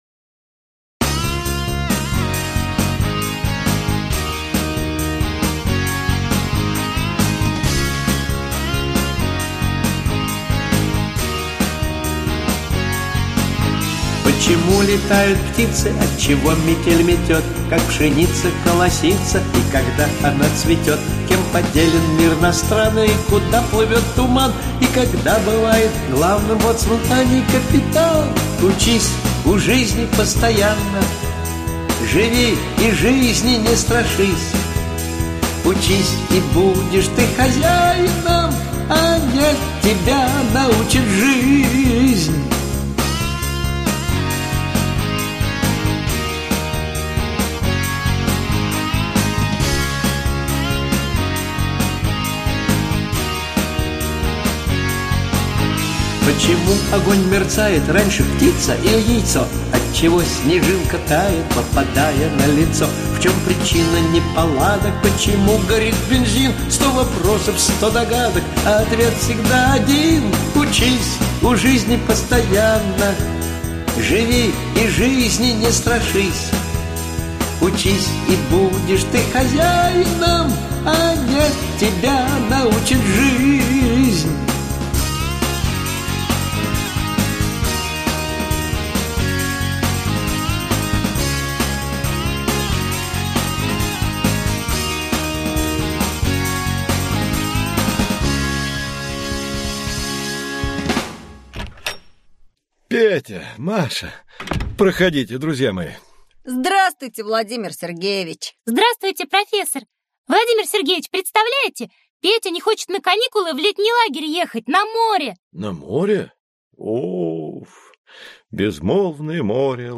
Аудиокнига Транспорт: Корабли | Библиотека аудиокниг